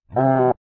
animalworld_yak.ogg